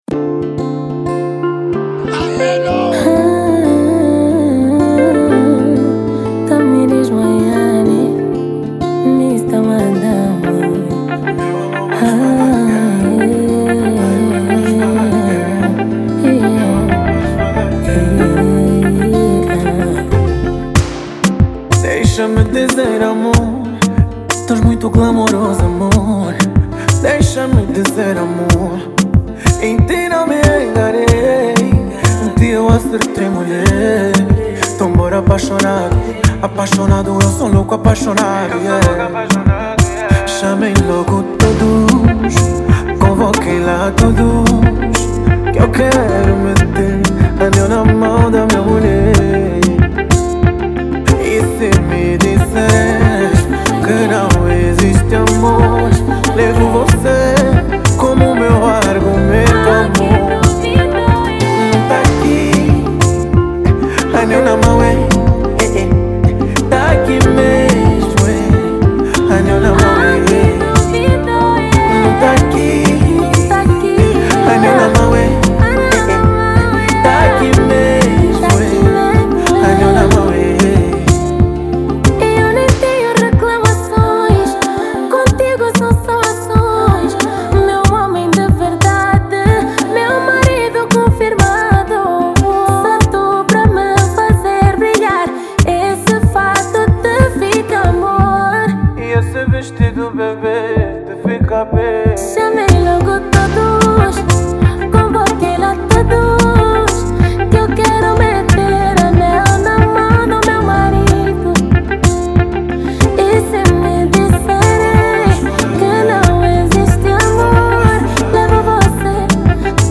Genero: Pop